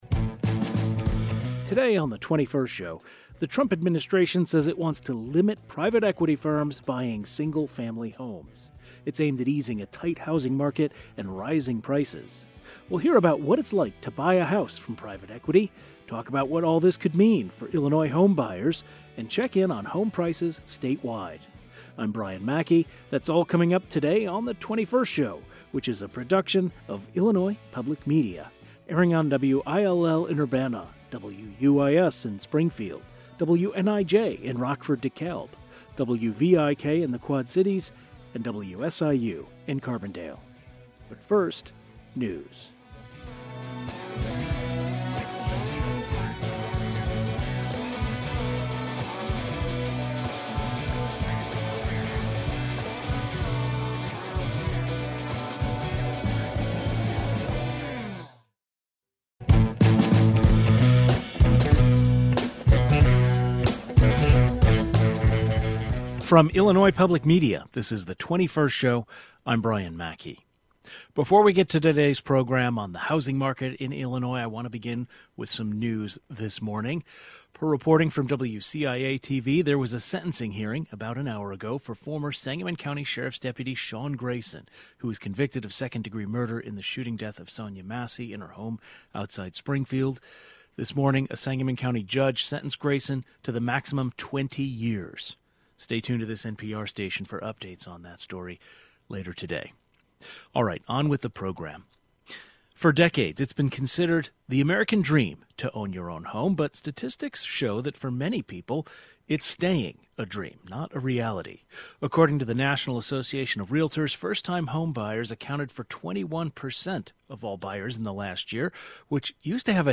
A journalist who covers housing, a real estate expert, an economics professor, and an energy and equity expert discuss what this could mean for homebuyers and current home prices across the state.